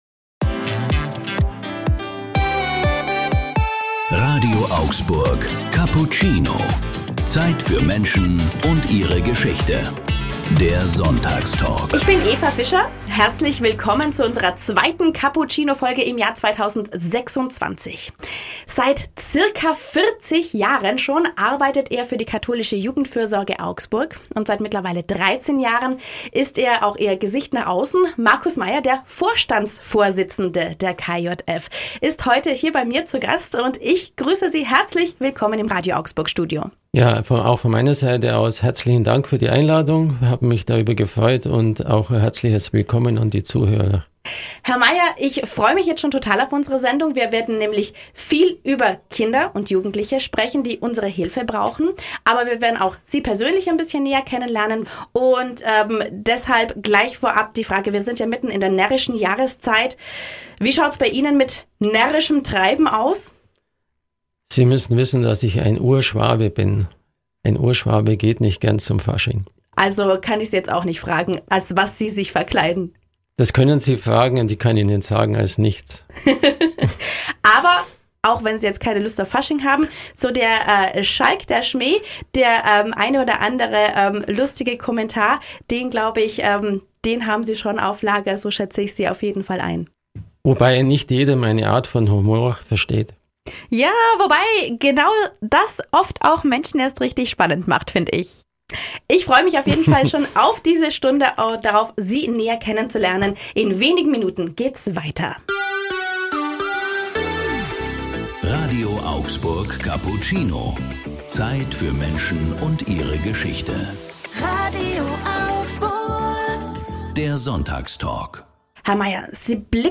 Radio Augsburg Sonntagstalk "Cappuccino"